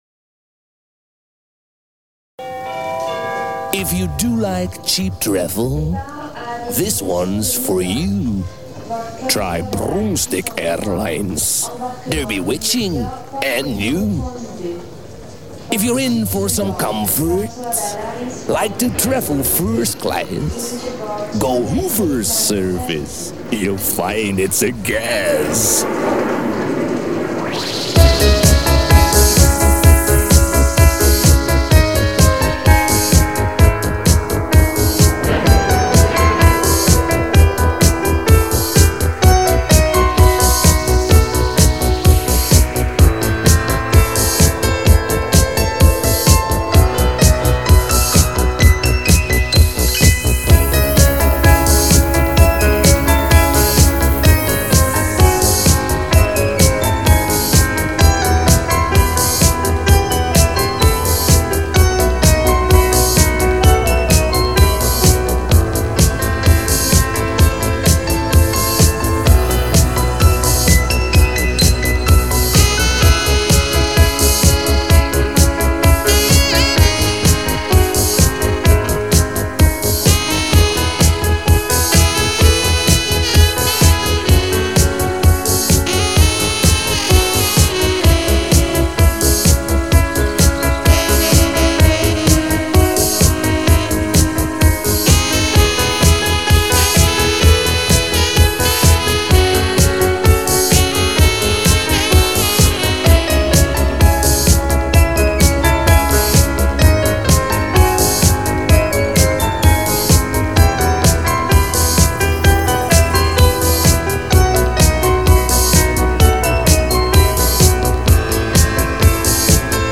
Запись с кассеты ,качества нет ,но композиция приятная .